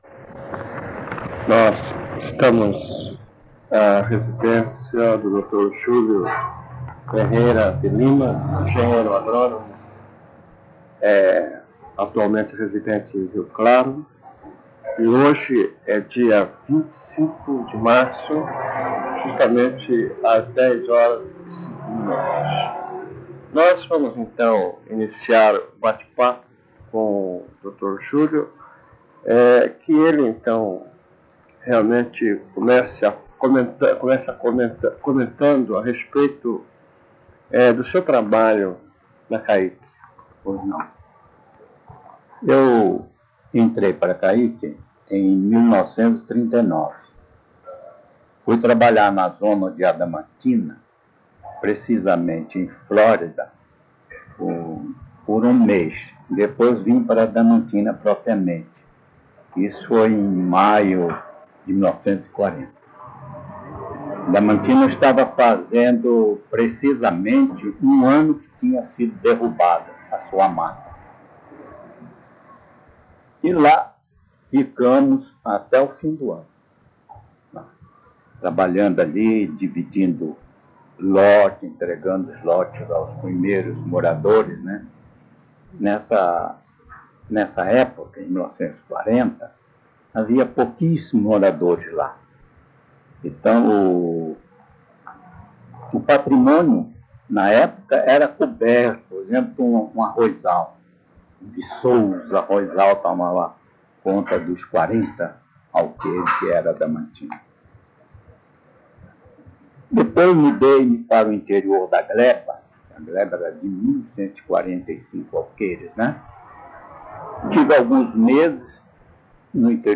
*Recomendado ouvir utilizando fones de ouvido.